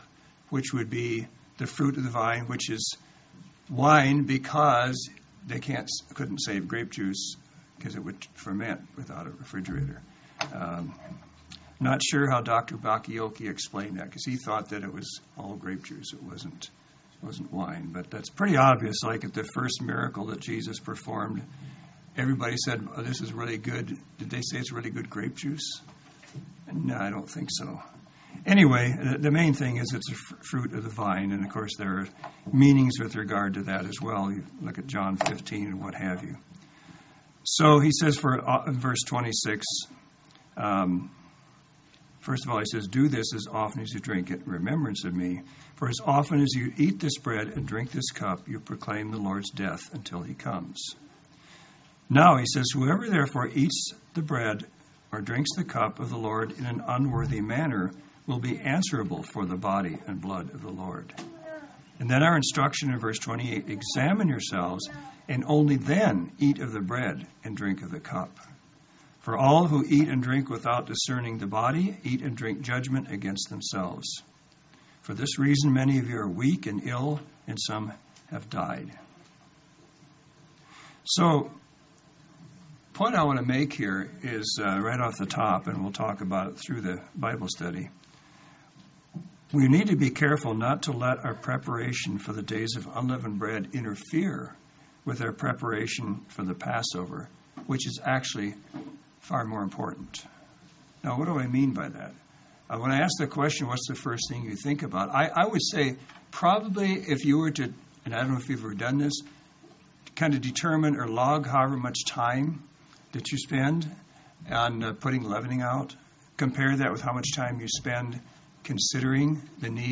Sermons
Given in Central Oregon